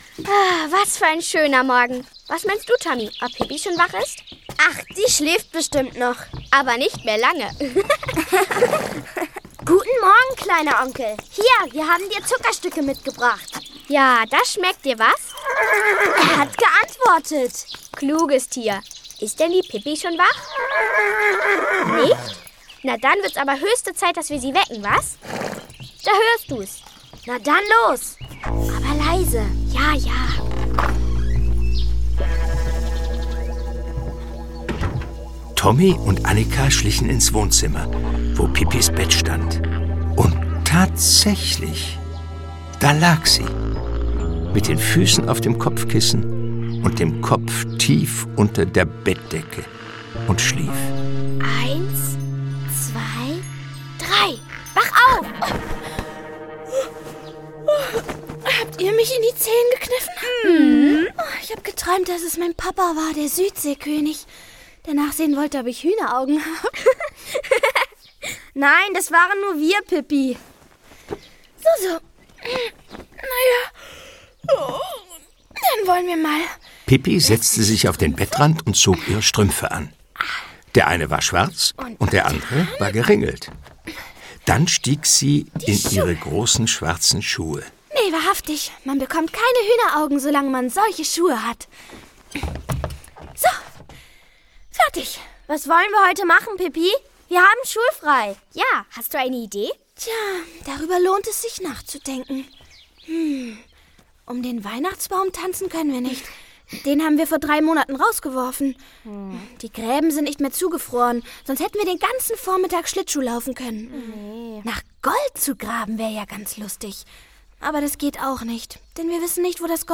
Pippi Langstrumpf geht an Bord - Das Hörspiel - Astrid Lindgren - Hörbuch